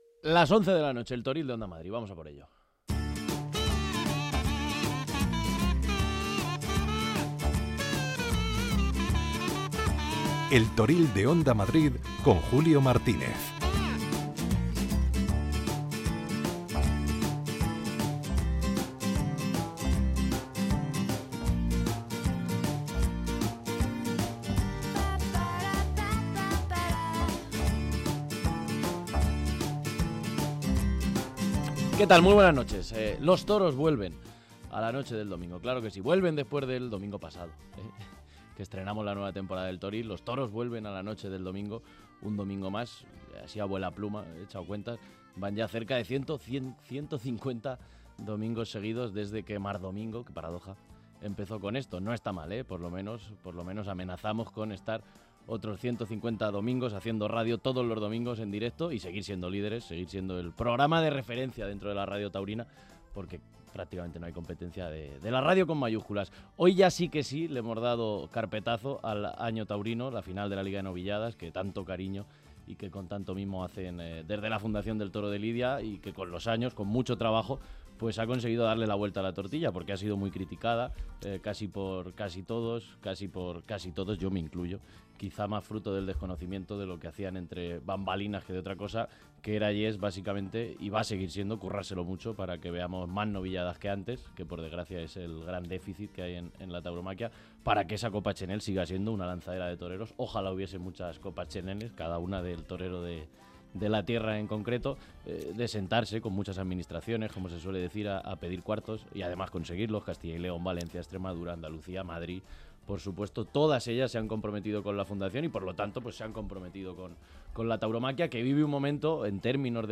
Habrá información pura y dura y entrevistas con los principales protagonistas de la semana.